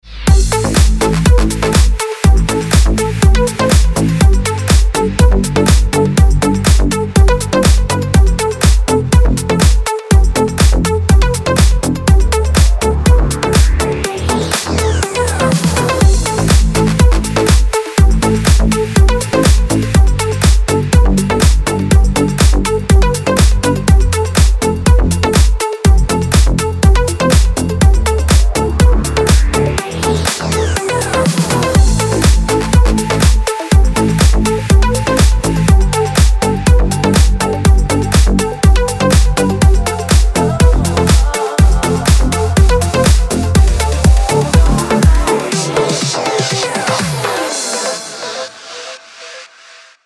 • Качество: 256, Stereo
deep house
Indie Dance